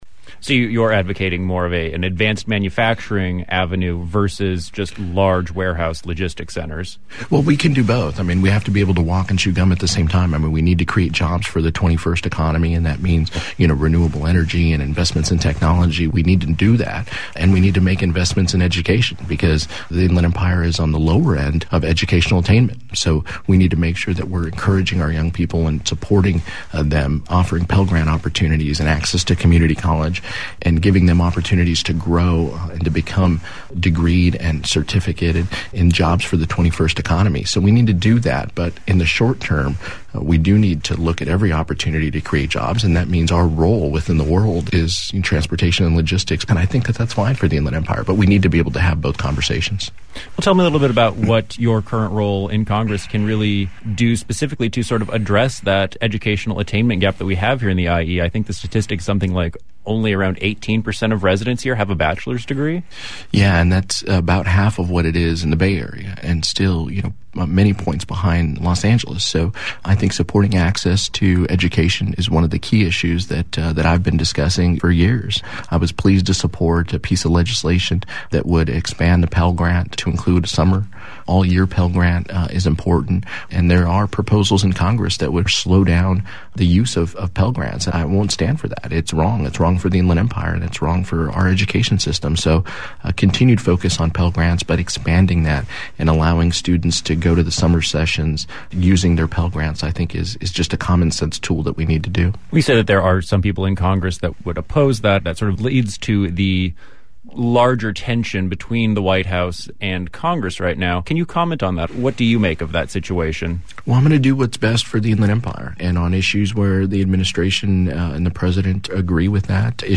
This is Part 2 of a 2-part interview.